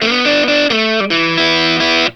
BLUESY3 E 90.wav